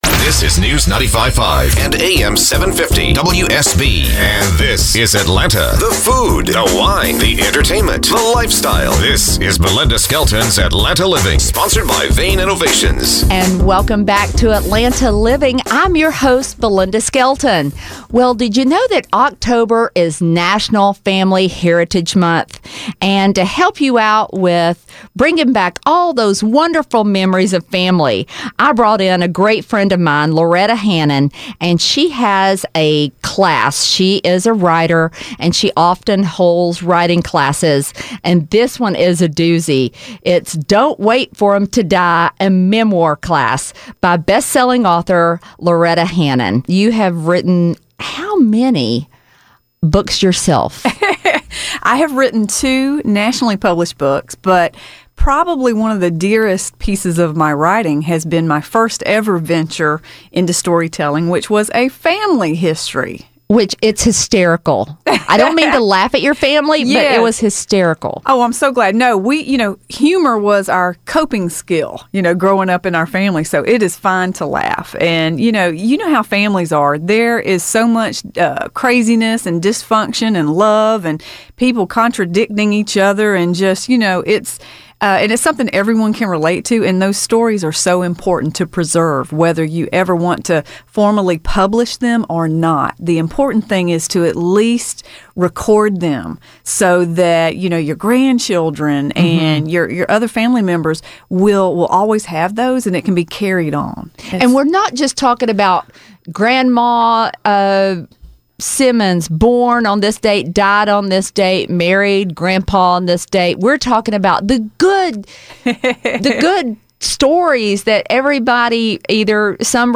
Listen to my WSB Radio interview about this class.